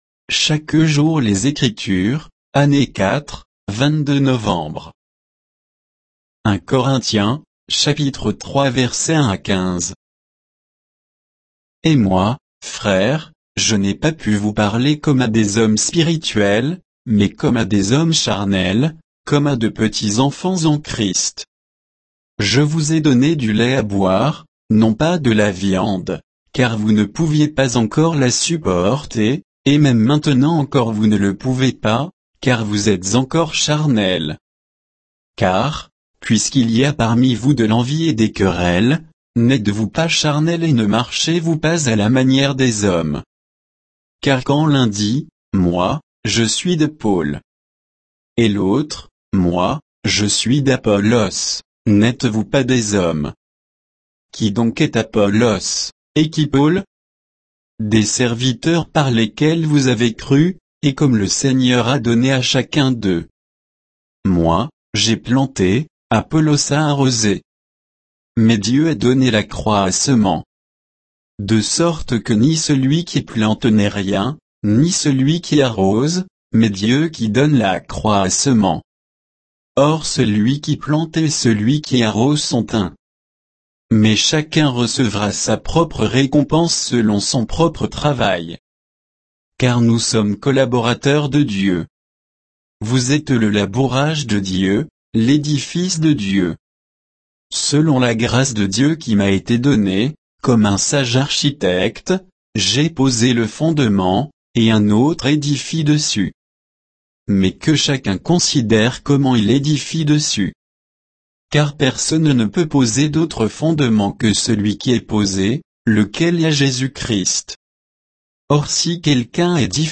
Méditation quoditienne de Chaque jour les Écritures sur 1 Corinthiens 3